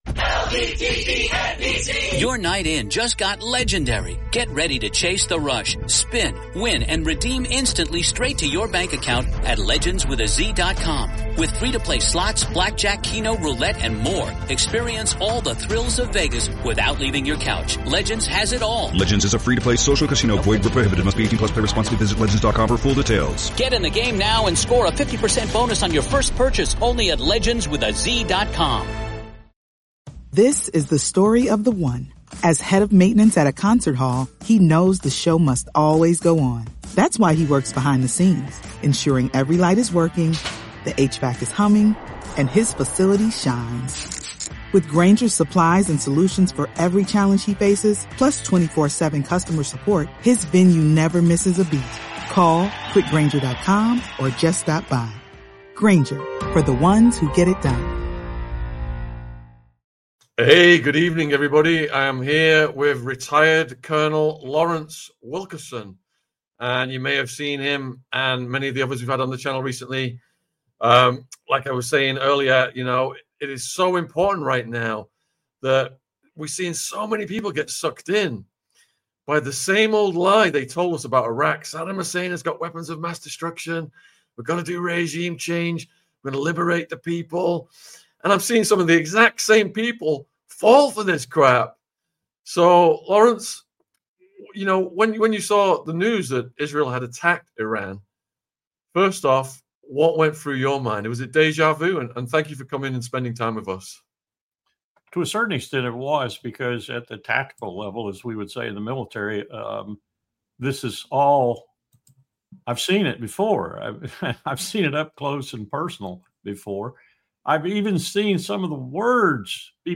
WW3? ESCALATION? Will USA Attack Iran? Col Larry Wilkerson LIVE | AU 423